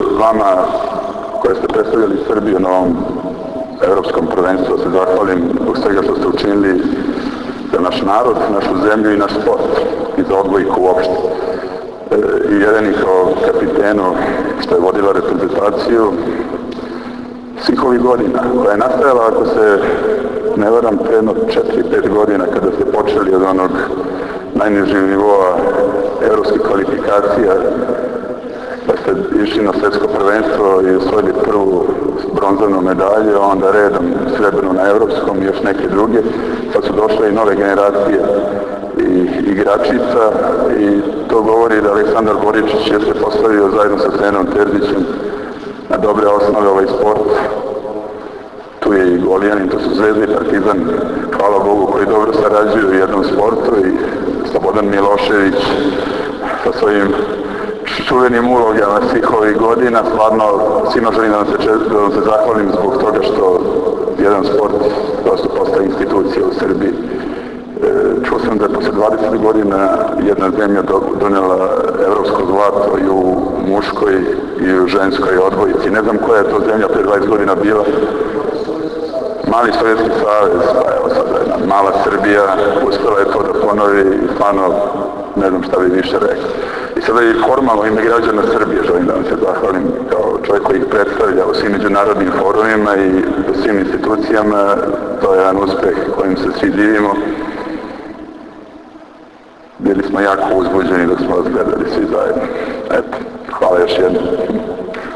Boris Tadić, predsednik Srbije, priredio je danas u Novom dvoru prijem za odbojkašice Srbije i delegaciju Odbojkaškog saveza Srbije, koju je predvodio Aleksandar Boričić, predsednik OSS.
IZJAVA BORISA TADIĆA, PREDSEDNIKA SRBIJE